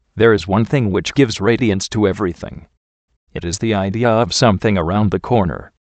Location: USA
How do you pronounce this word?